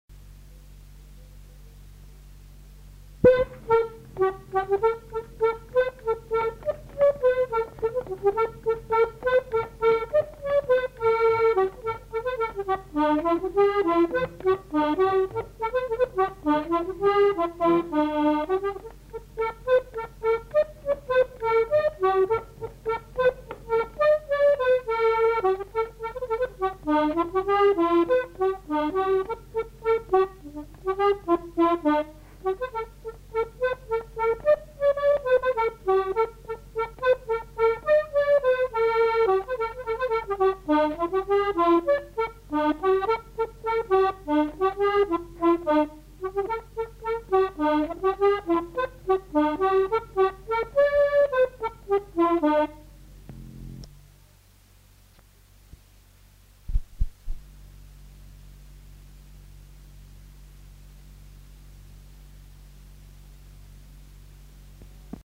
Aire culturelle : Haut-Agenais
Genre : morceau instrumental
Instrument de musique : accordéon diatonique
Danse : bourrée